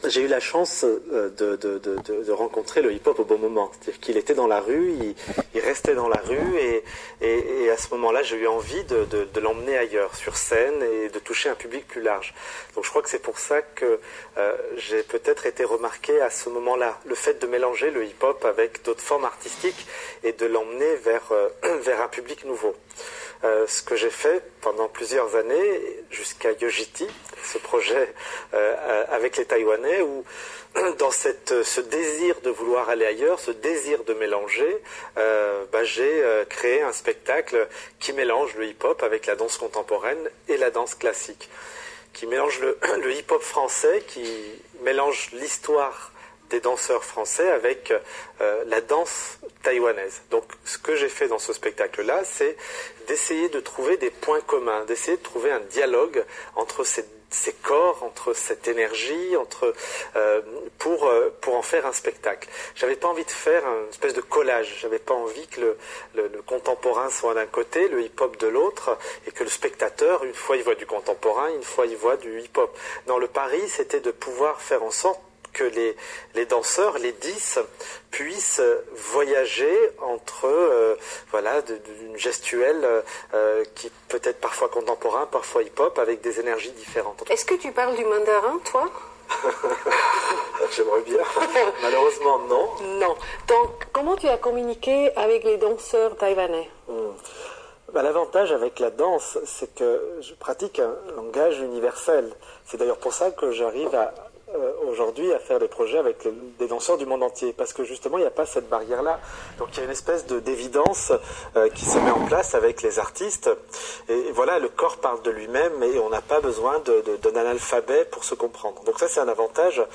Voici un autre extrait d’un entretien enregistré lors d’un festival de danse en Suisse.